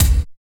81 IND KIK-R.wav